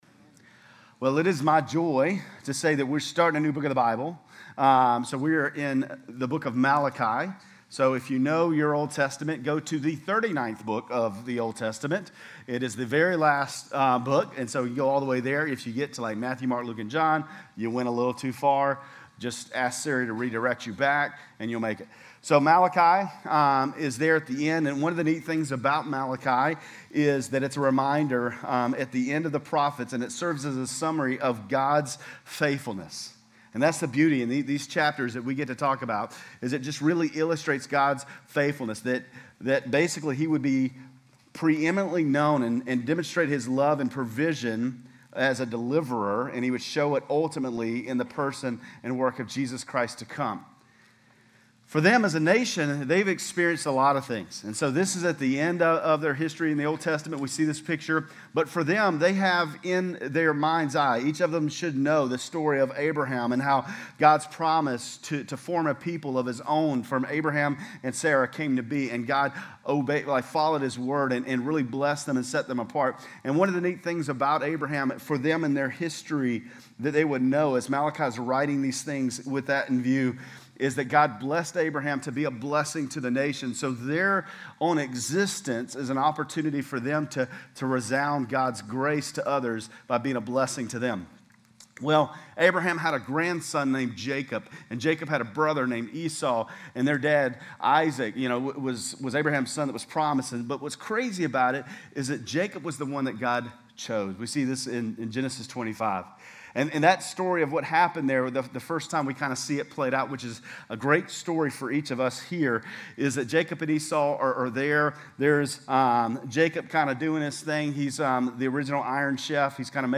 GCC-Lindale-October-9-Sermon.mp3